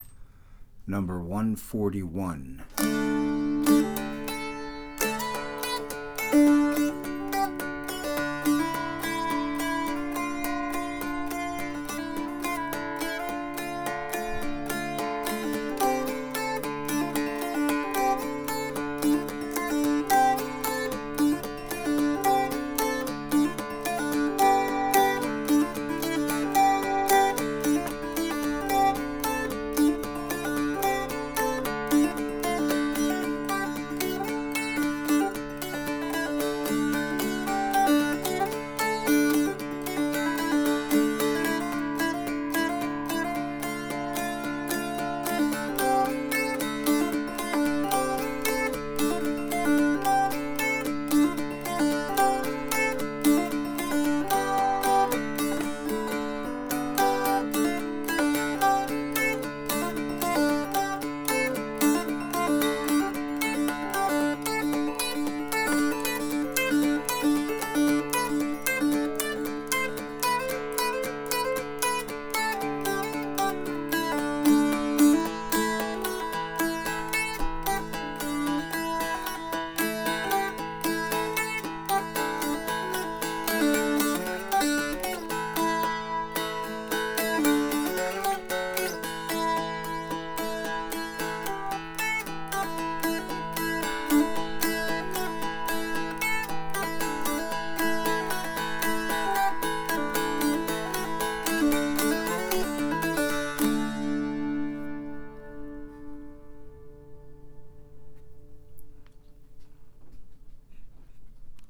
# 141 Electric Hourglass Dulcimer - Heinrich Dulcimers
Light and airy but with some guts, this sweatheart will dazzle your friends with her looks and her voice.
Click on the play button below to hear this dulcimer.